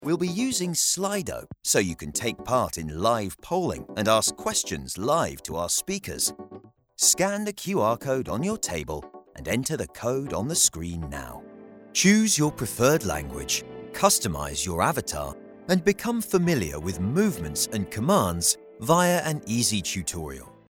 Apprentissage en ligne
Microphone à valve à condensateur cardioïde Sontronics Aria
Microphone dynamique SHURE SM7B